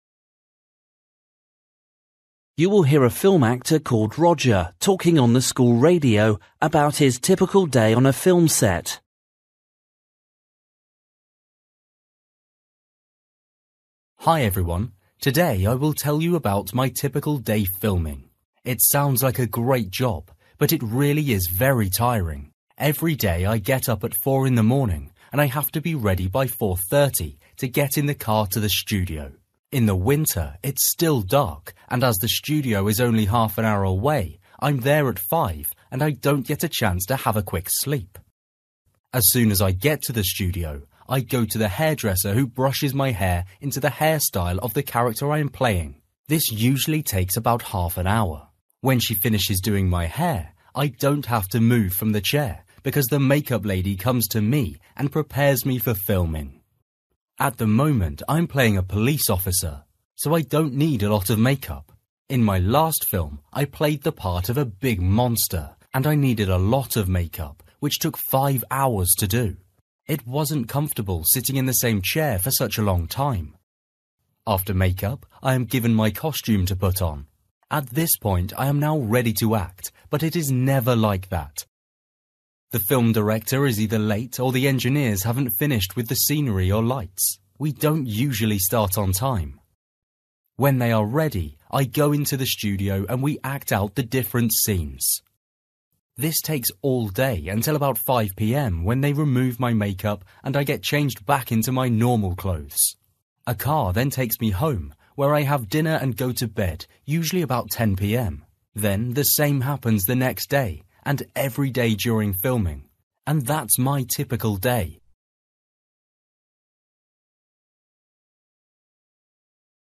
Listening: a film actor